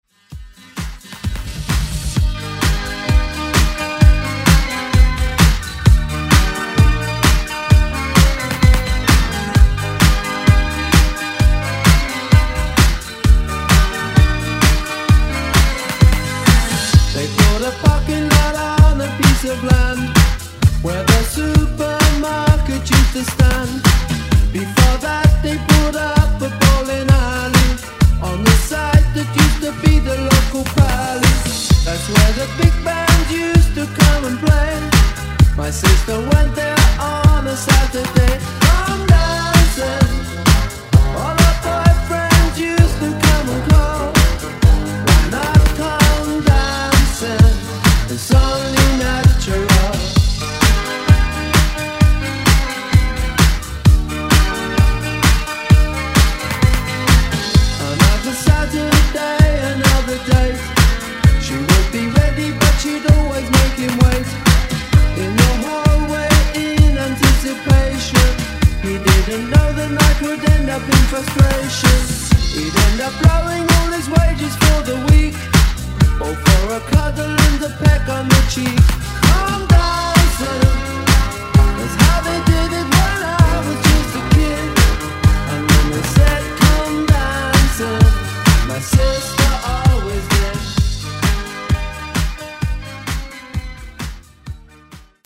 Genres: 80's , RE-DRUM
Clean BPM: 130 Time